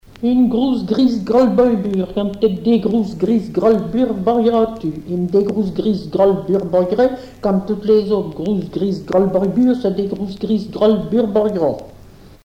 comptine
Pièce musicale éditée